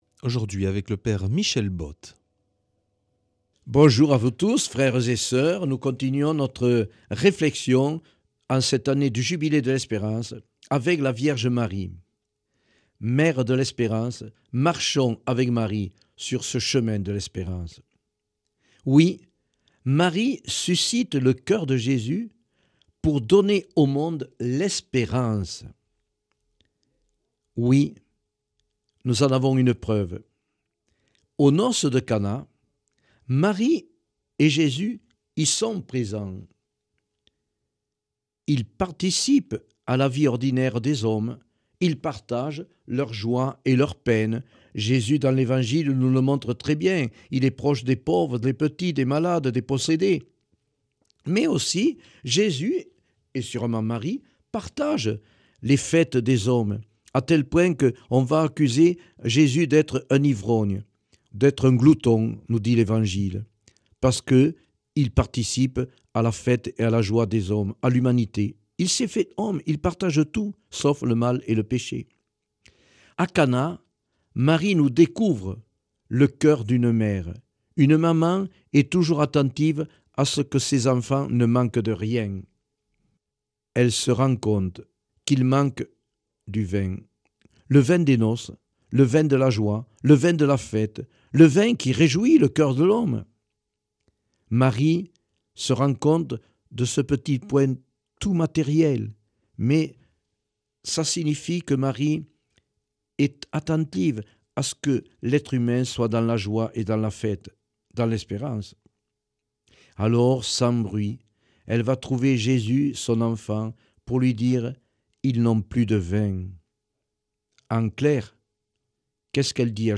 mercredi 30 juillet 2025 Enseignement Marial Durée 10 min